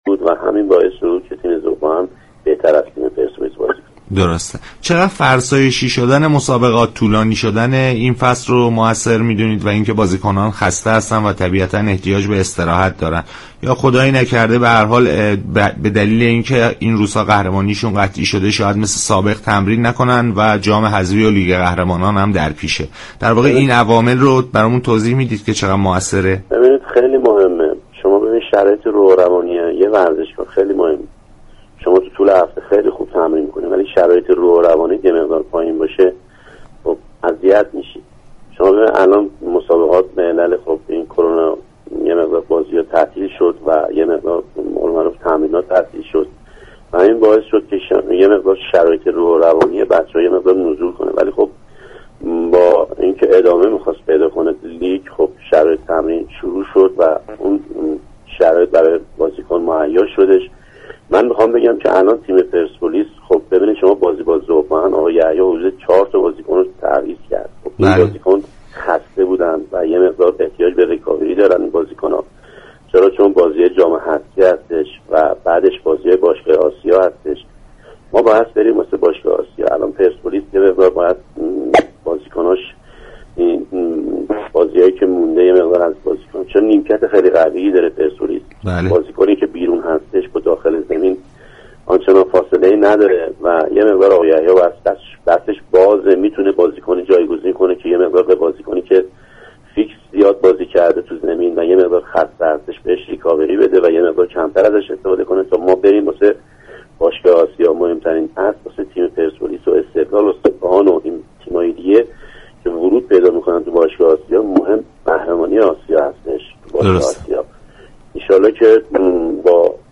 شما می توانید از طریق فایل صوتی پیوست شنونده این گفتگو باشید.